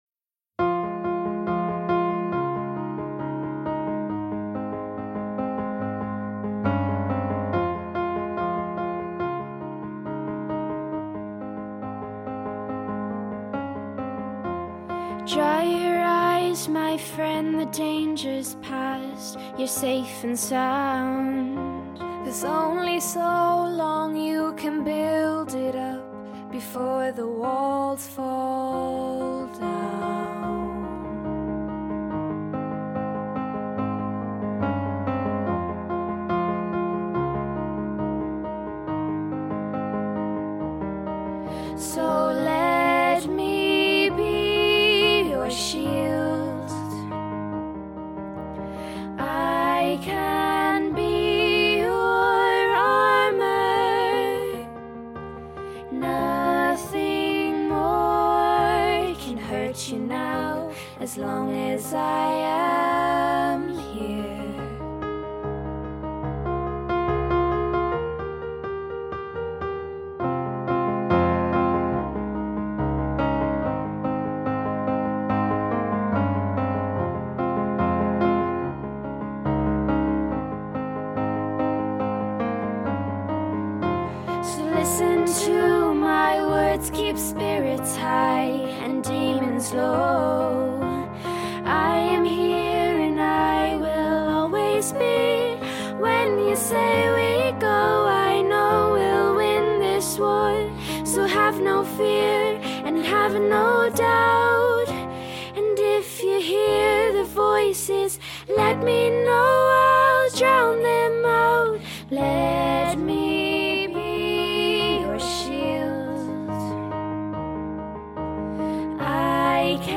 Story telling through song
an original song